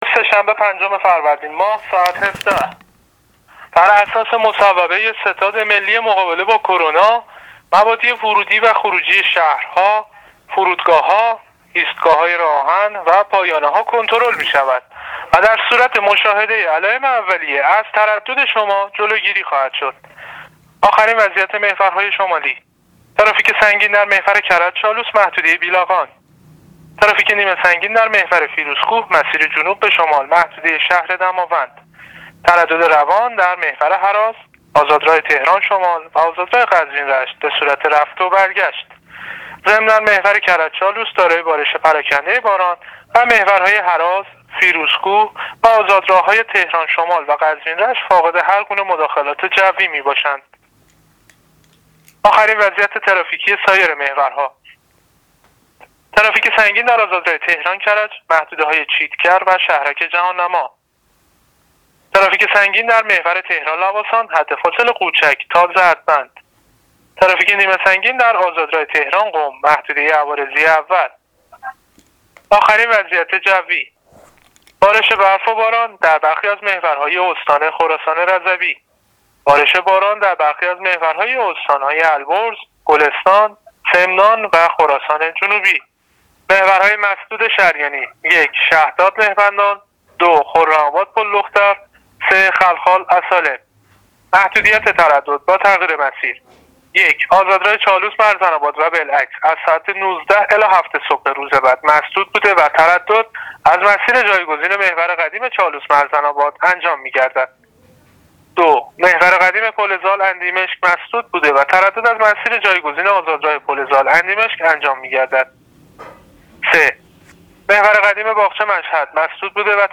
گزارش رادیو اینترنتی از آخرین وضعیت ترافیکی جاده‌ها تا ساعت ۱۷ پنجم فروردین ۱۳۹۹: